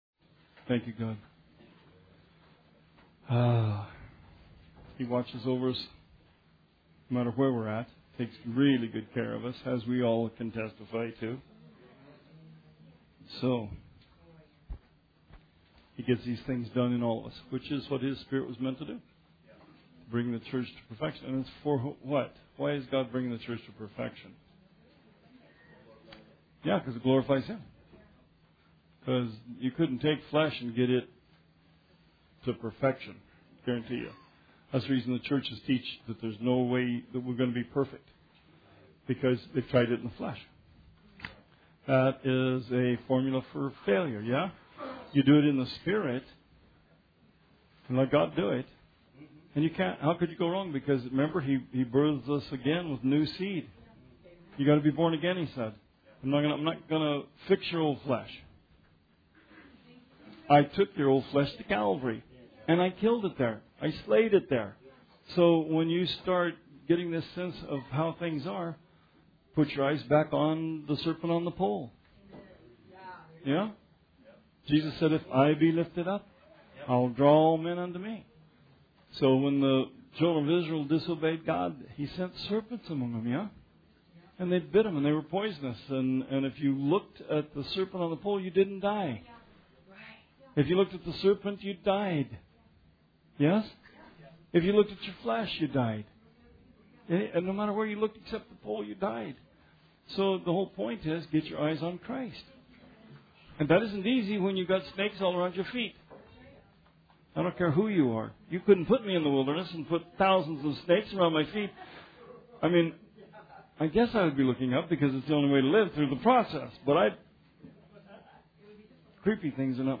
Bible Study 7/25/18